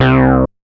MoogResPlus 005.WAV